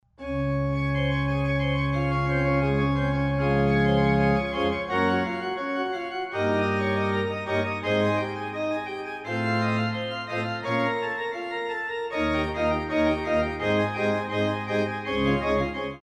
Builder: Silbermann (Gottfried)Type: Historic German baroque organ